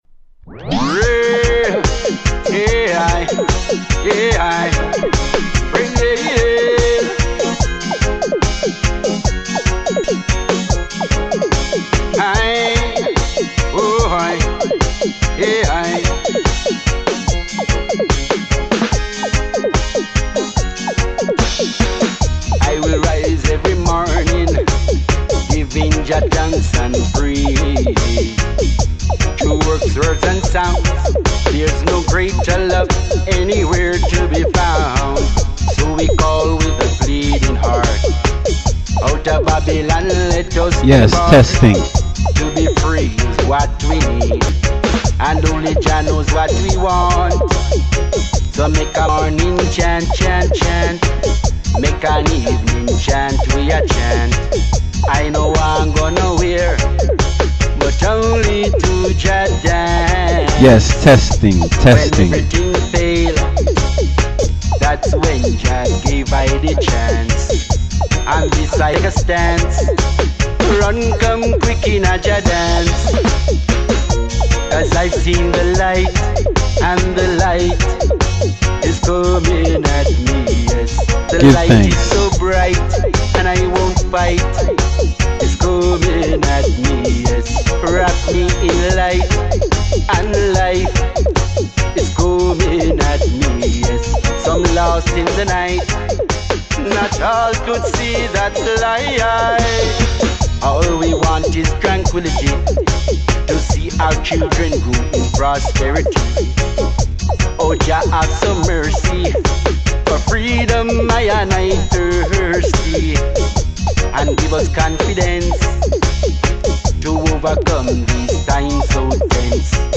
ROOTS & CULTURE / DUB / STEPPAS VIBES